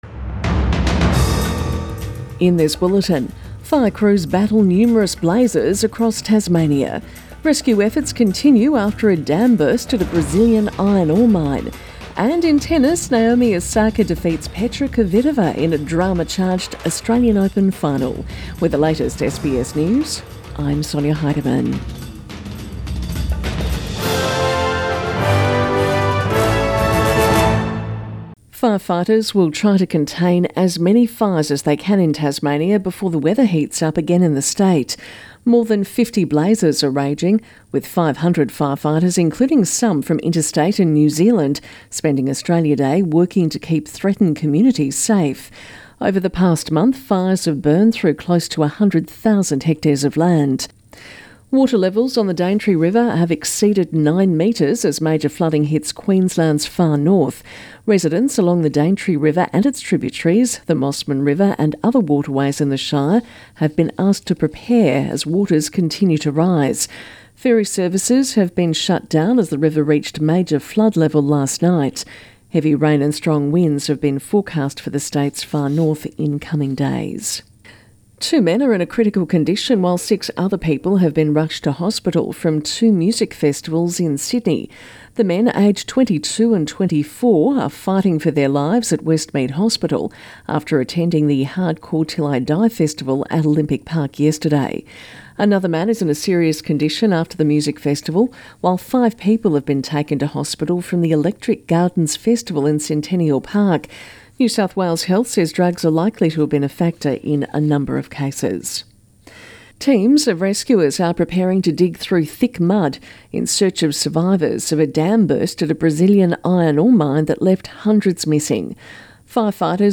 AM Bulletin 27 January